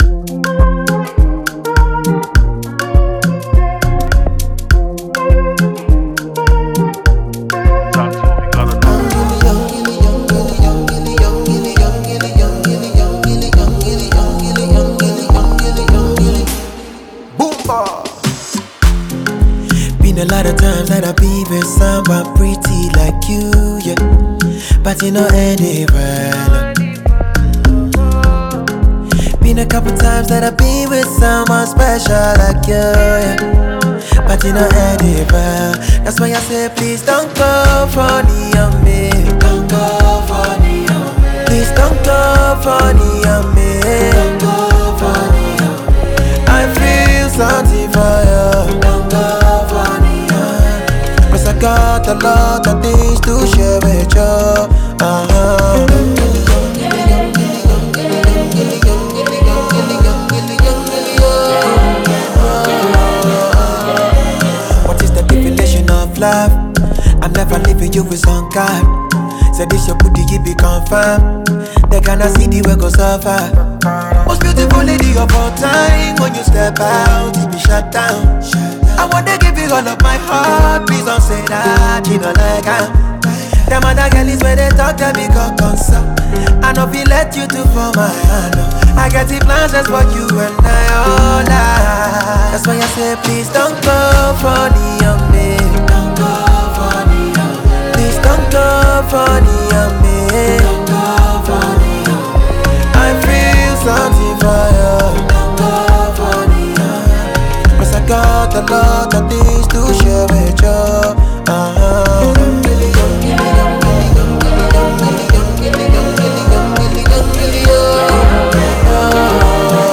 mid-tempo Afrofusion
Rich reverb blankets both the lead and
Delicate, expressive strums from the lead guitar